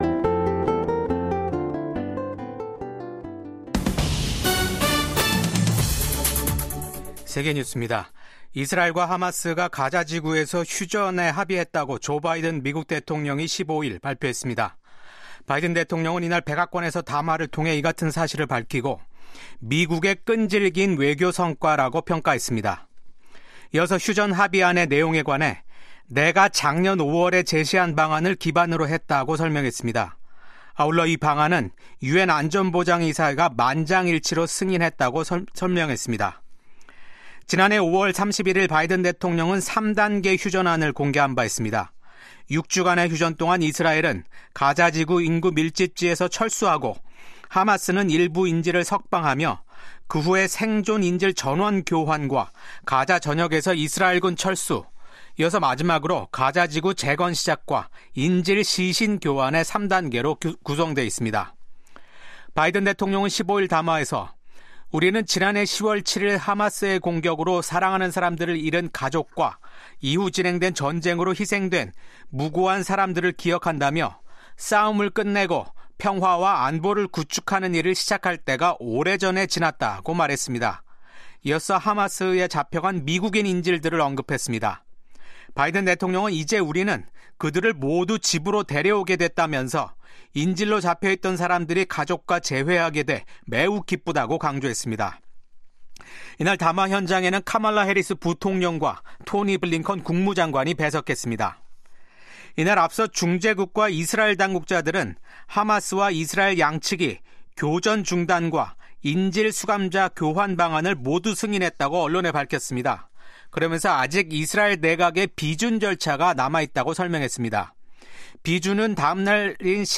VOA 한국어 아침 뉴스 프로그램 '워싱턴 뉴스 광장'입니다. 비상계엄 선포로 내란죄 혐의를 받고 있는 윤석열 대통령이 현직 대통령으론 한국 헌정사상 처음 사법기관에 체포됐습니다. 미국 백악관은 윤석열 한국 대통령이 체포된 데 대해 “미국은 한국 국민에 대한 지지를 확고히 한다”고 밝혔습니다. 미국의 전문가는 트럼프 정부가 혼란 상태에 빠진 한국 정부와 협력하는 것은 어려울 것이라고 전망했습니다.